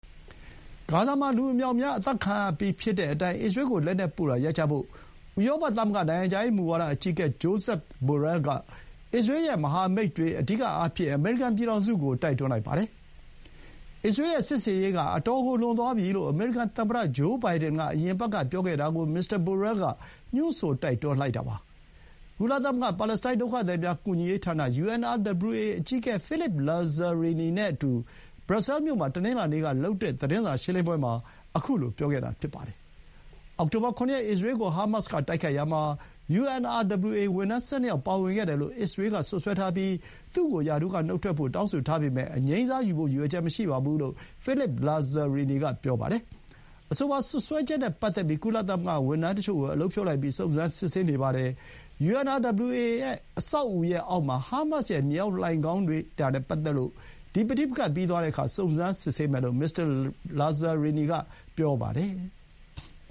အစ္စရေးရဲ့စစ်ဆင်ရေးက အတော်ကိုလွန်သွားပြီလို့ အမေရိကန်သမ္မတဂျိုးဘိုင်ဒန်က အရင်အပတ်ကပြောခဲ့တာကို မစ္စတာဘိုရဲလ်က ညွှန်းဆိုတိုက်တွန်းလိုက်တာပါ။ ကုလသမဂ္ဂ ပါလက်စတိုင်းဒုက္ခသည်များ ကူညီရေးဌာန UNRWA အကြီးအကဲ ဖိလစ်ပ် လာဇာရီနီ (Philippe Lazzarini) နဲ့အတူ ဘရပ်ဆဲမြို့မှာ တနင်္လာနေ့က သတင်းစာရှင်းလင်းပွဲအတွင်း ခုလို ပြောခဲ့တာဖြစ်ပါတယ်။ အောက်ဆိုဘာ ၇ ရက် အစ္စရေးကို ဟာမတ်စ်က တိုက်ခိုက်ရာမှာ UNRWA ဝန်ထမ်း ၁၂ ယောက်ပါဝင်ခဲ့တယ်လို့ အစ္စရေးက စွပ်စွဲထားပြီး သူ့ကို ရာထူးကနုတ်ထွက်ဖို့ တောင်းဆိုထားပေမဲ့ အငြိမ်းစားယူဖို့ ရည်ရွယ်ချက်မရှိဘူးလို့ ဖိလစ်ပ် လာဇာရီနီ က ပြောပါတယ်။